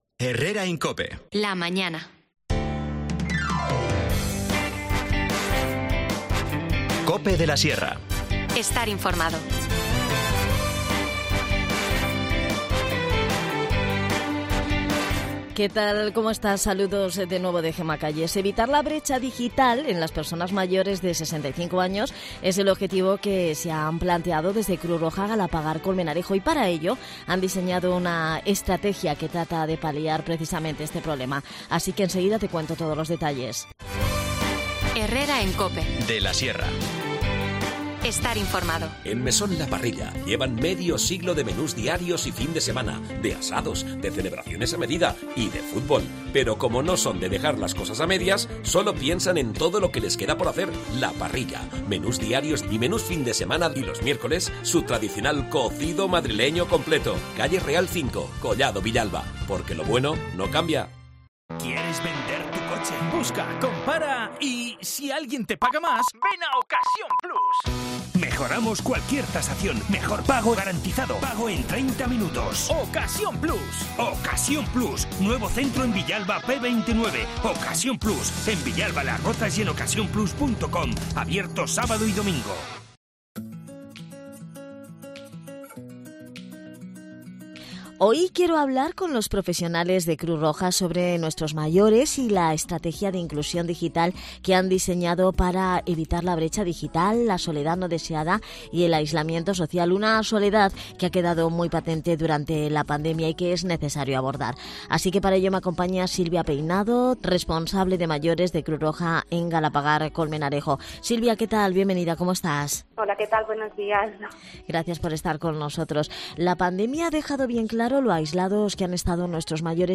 Te contamos las últimas noticias de la Sierra de Guadarrama con los mejores reportajes y los que más te interesan y las mejores entrevistas, siempre pensando en el oyente.